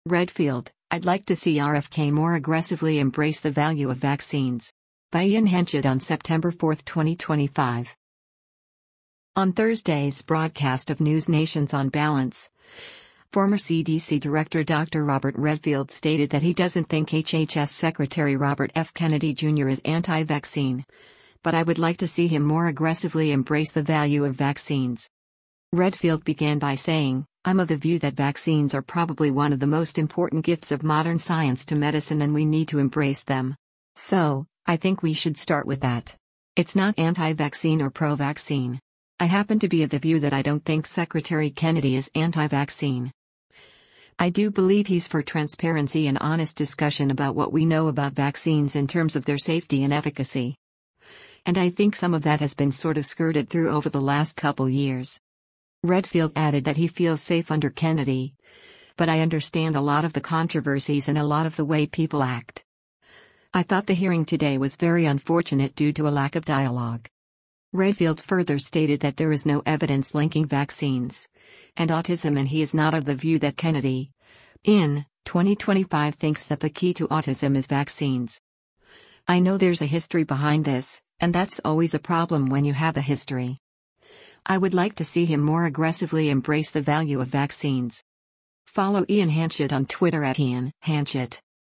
On Thursday’s broadcast of NewsNation’s “On Balance,” former CDC Director Dr. Robert Redfield stated that he doesn’t think HHS Secretary Robert F. Kennedy Jr. is anti-vaccine, but “I would like to see him more aggressively embrace the value of vaccines.”